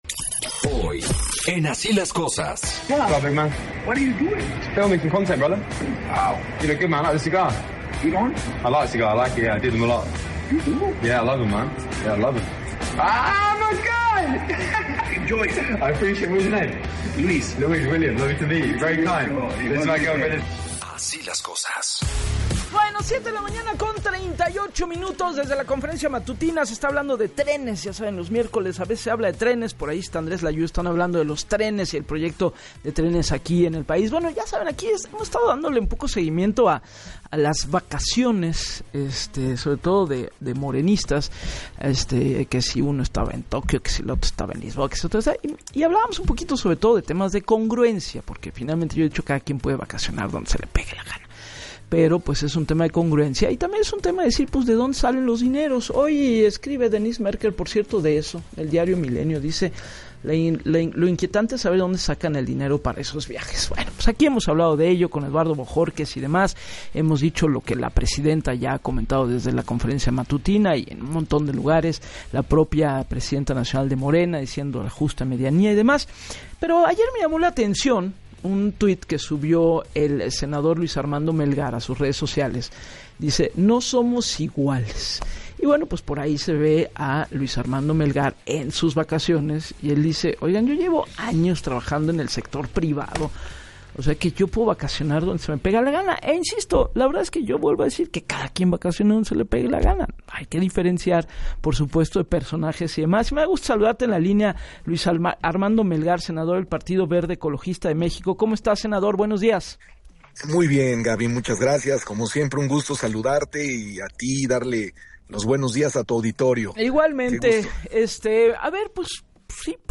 En entrevista para “Así las Cosas” con Gabriela Warkentin, criticó a los políticos del partido en el poder señalando “lo que hoy existe en Morena es una austeridad hipócrita, porque en el sexenio anterior hablaban del ‘no somos iguales”.